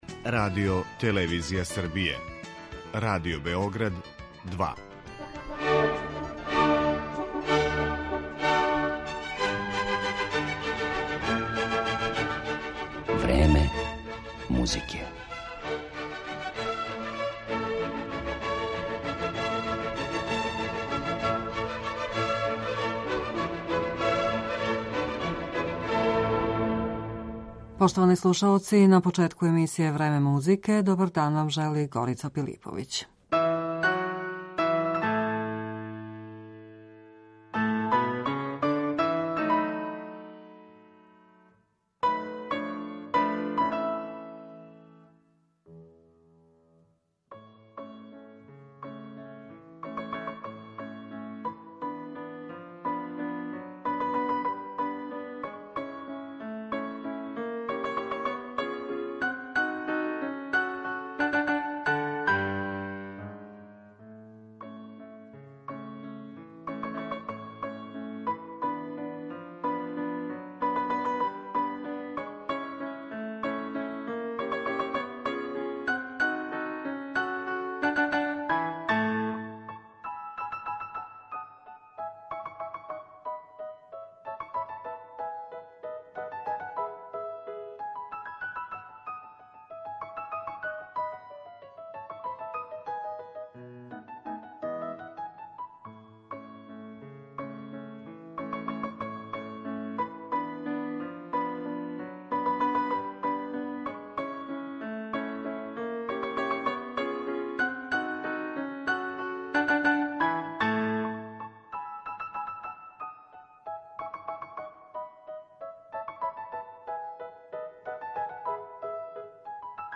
Његови инструменти, дакле, једноставно певају, а то ће у данашњој емисији, између осталих, показати кларинет, обоа д' аморе, клавир итд. У центру пажње биће Доницетијева камерна музика попут клавирског трија, гудачког квартета и др.